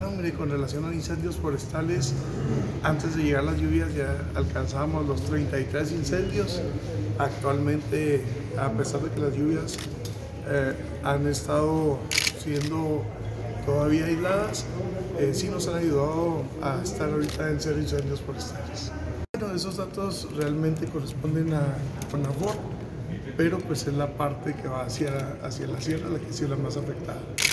Audio. Luis Corral Torresdey, coordinador estatal de Protección Civil.